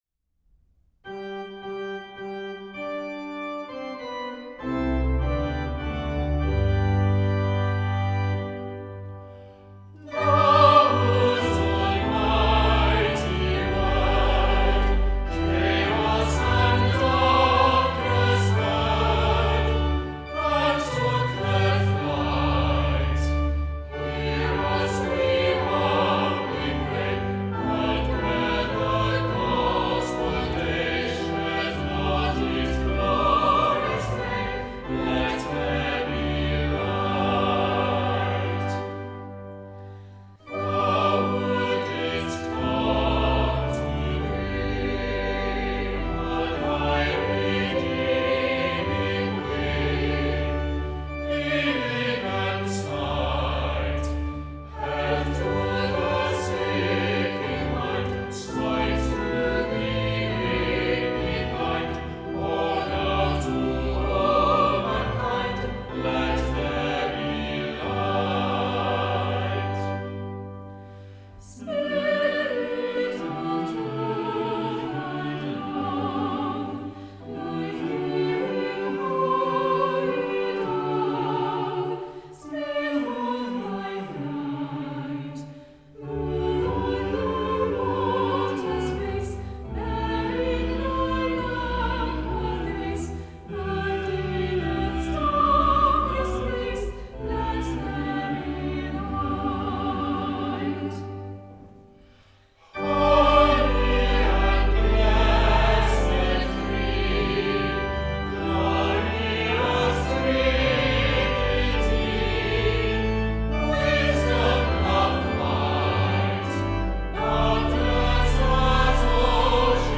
Gospel Hymn; Thou, whose almighty word